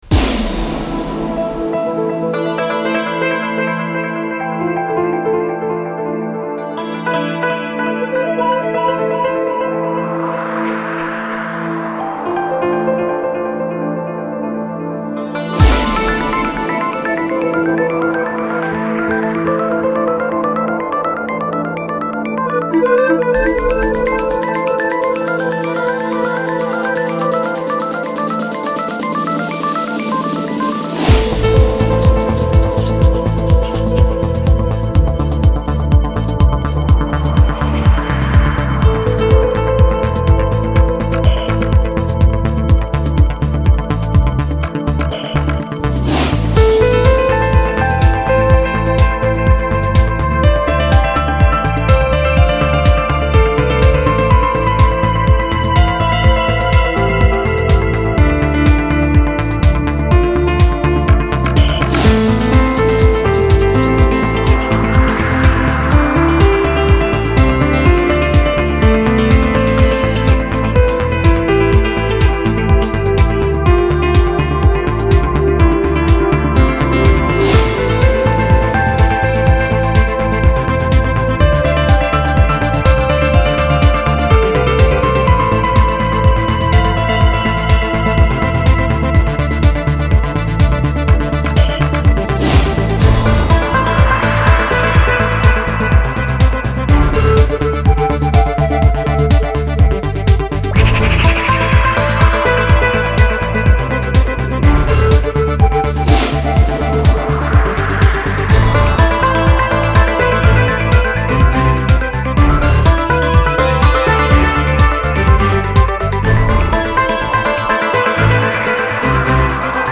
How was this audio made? • Category: Sound 5.1